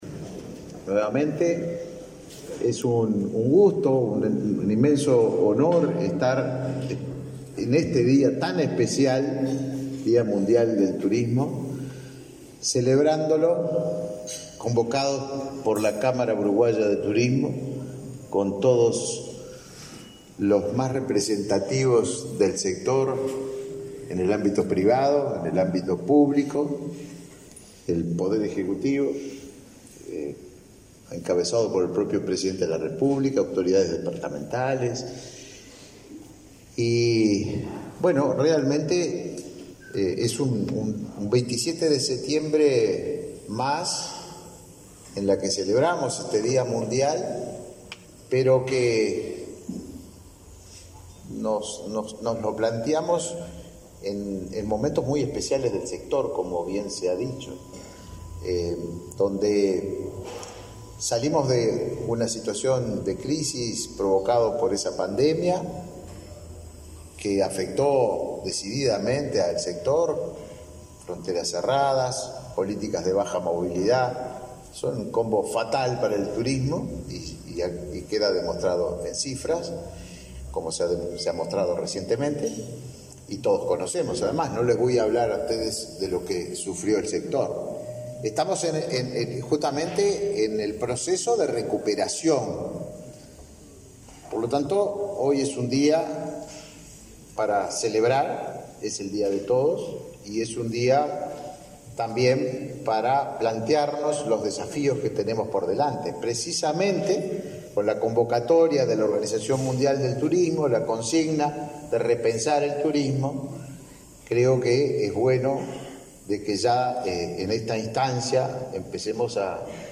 Conferencia de prensa por el Día Mundial del Turismo
Con la presencia del presidente de la República, Luis Lacalle Pou, se realizó, este 27 de setiembre, el acto por el Día Mundial del Turismo.
Disertaron en el evento el ministro de Turismo, Tabaré Viera; el ministro de Trabajo y Seguridad Social, Pablo Mieres, y la ministra de Economía y Finanzas, Azucena Arbeleche.